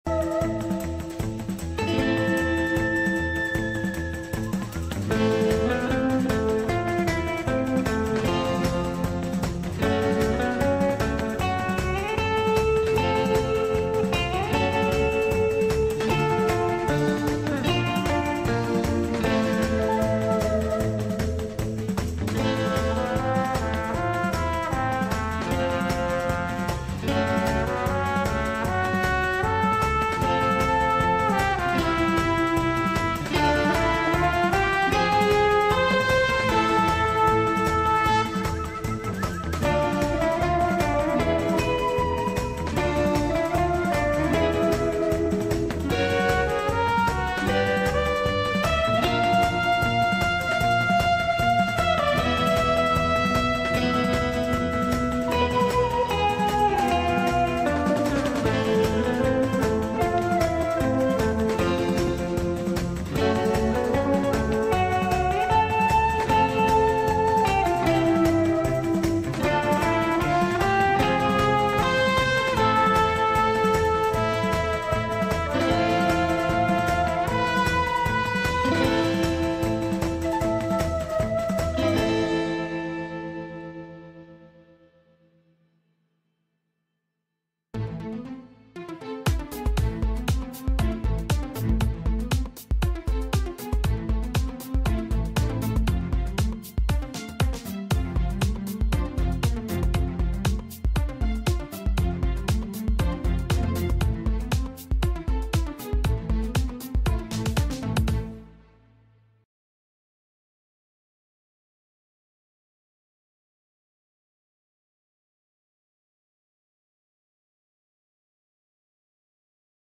Александр Плющев обсудит с экспертами в прямом эфире The Breakfast Show все главные новости. Ждать ли нового раунда переговоров в Стамбуле?